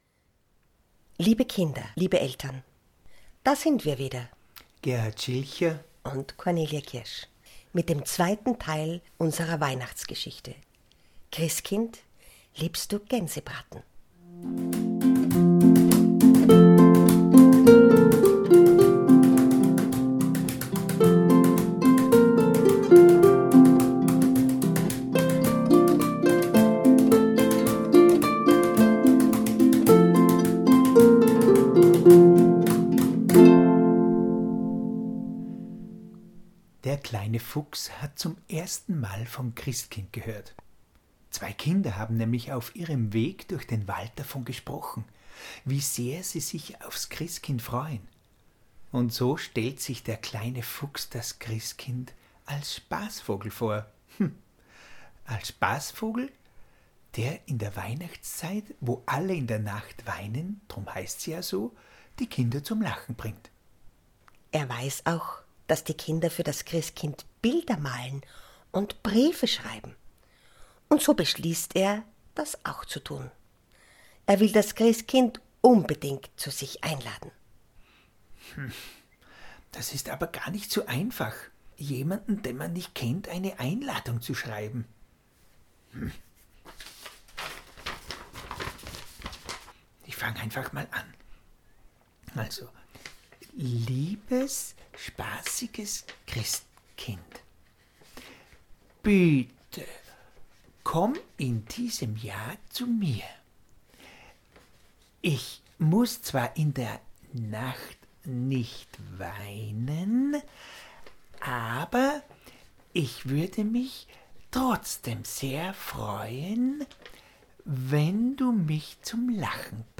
Adventhörspiel in vier Teilen, nicht nur für Kinder